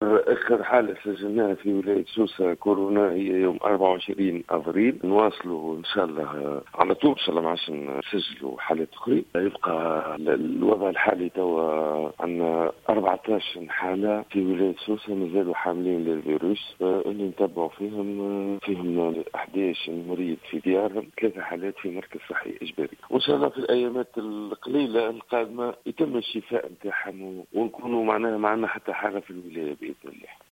أكد المدير الجهوي للصحة بسوسة، سامي الرقيق في تصريح اليوم لـ"الجوهرة أف أم" عدم تسجيل إصابات جديدة بفيروس كورونا في الجهة منذ تاريخ 24 أفريل الماضي.